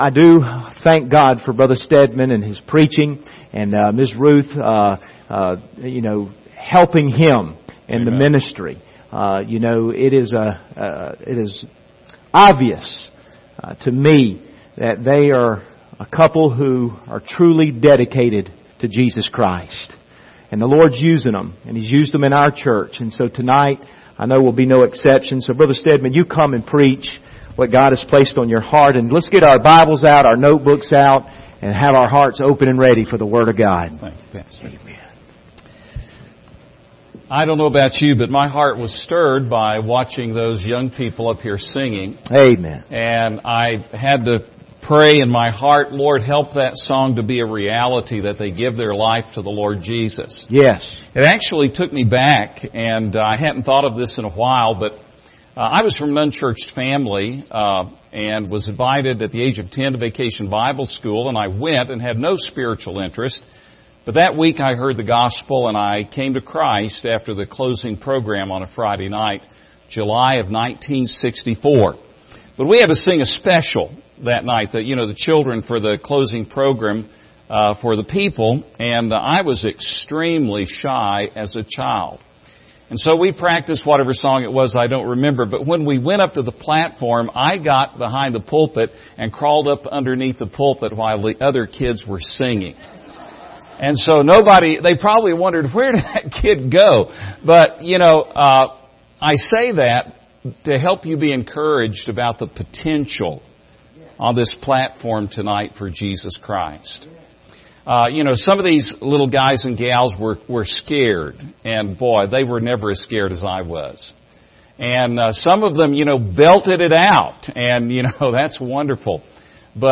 Series: 2021 Missions Conference Passage: Revelation 22:6-13 Service Type: Special Service